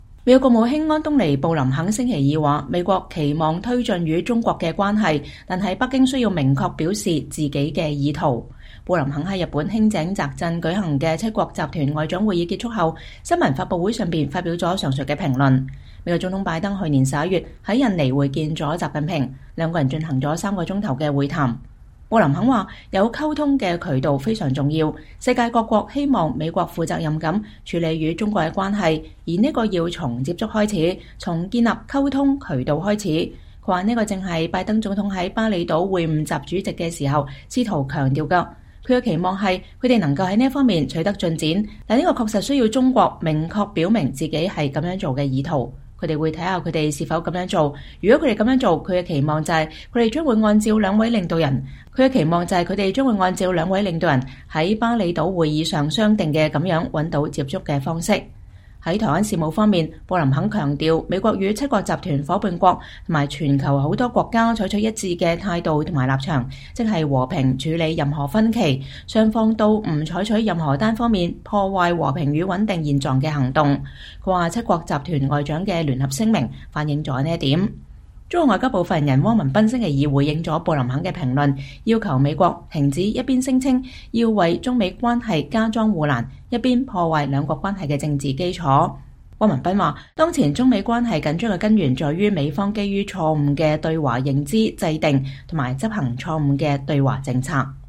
布林肯2023年4月18日在日本輕井澤鎮舉行的七國集團外長會議後的新聞發布會上講話。